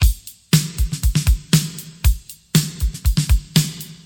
119 Bpm Drum Loop G# Key.wav
Free drum beat - kick tuned to the G# note.
119-bpm-drum-loop-g-sharp-key-E6b.ogg